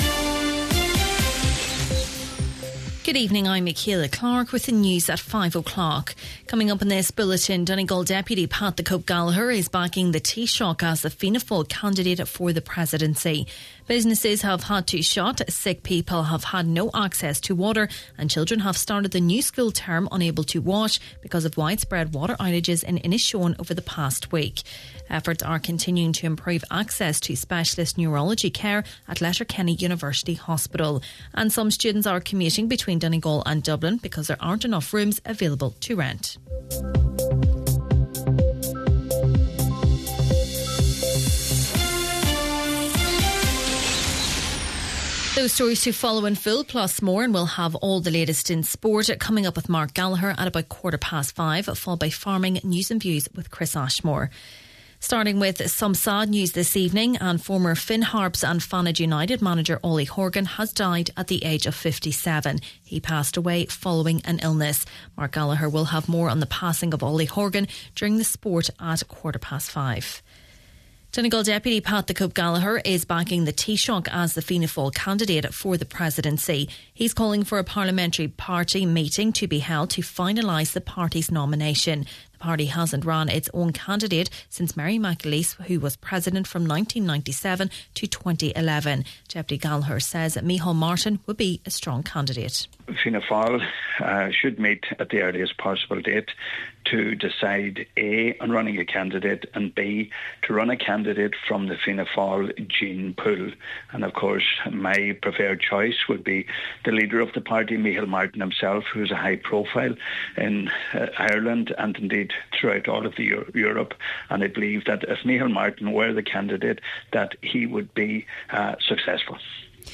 Main Evening News, Sport, Farming News and Obituaries – Thursday, August 28th